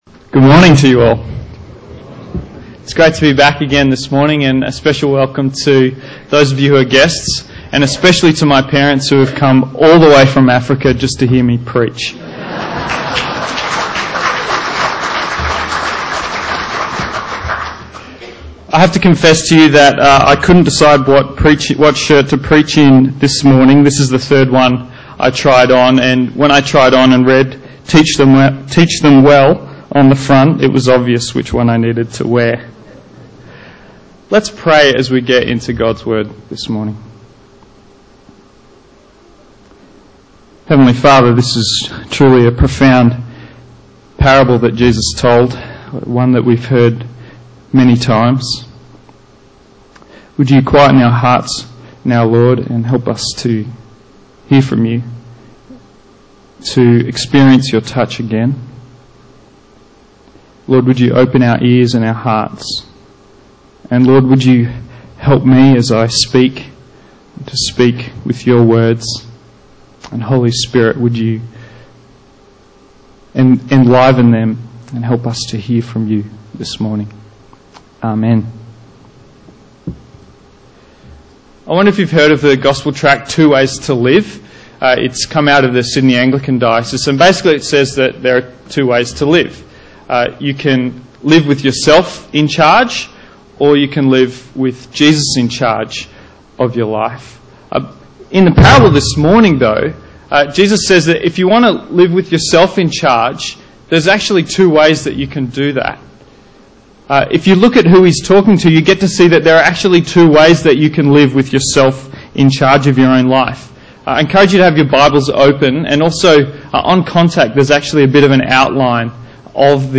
In this sermon